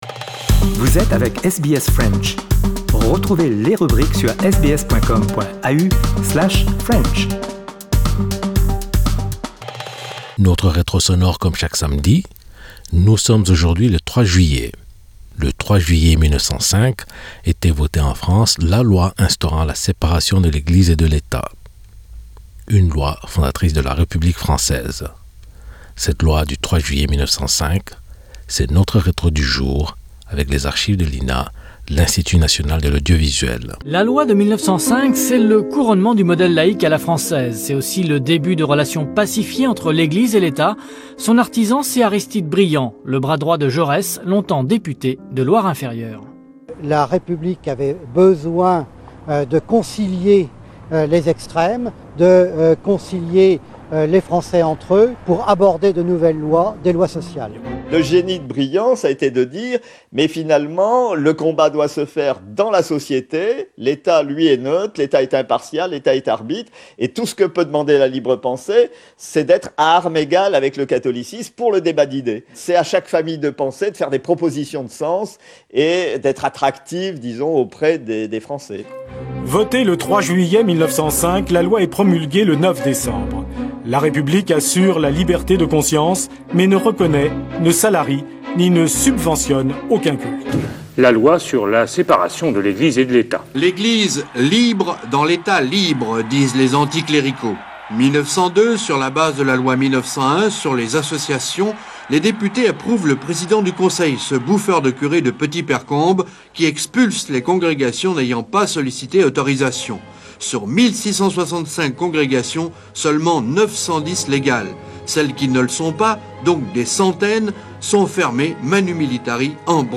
Notre retro sonore comme chaque samedi...
Le 3 juillet 1905 était votée en France la loi instaurant la séparation de l'Eglise et de l'Etat... une loi fondatrice de la République française... Cette loi du 3 juillet 1905 c'est notre retro du jour… avec les archives de l'INA. l'Institut National de l'Audiovisuel.